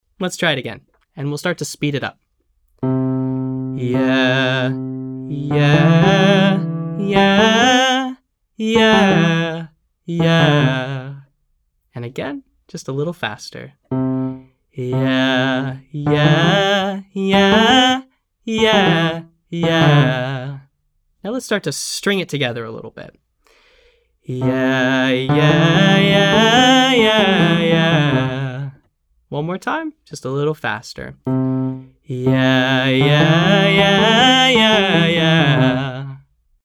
From: R&B Daily Practice for Low Voices
Our next exercise covers a slightly wider range and includes some repeated pitches.